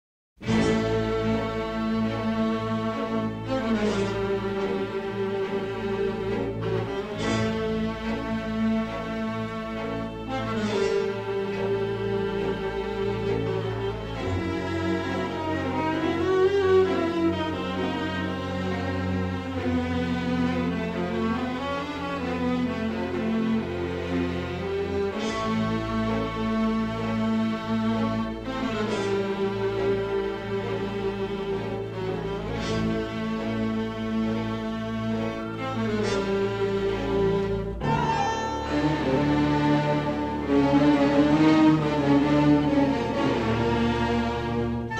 with harmonic-minor twists both sinister and mysterious.